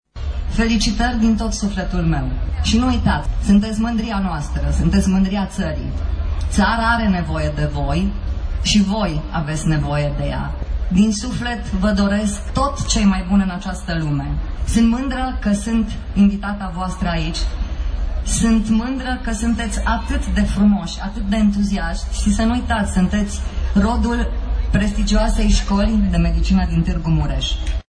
La festivitatea de premiere invitat special este ministrul Sănătății, Sorina Pintea.
Ministrul, în discursul rostit, i-a asigurat pe absolvenți de tot sprijinul și susținerea ei.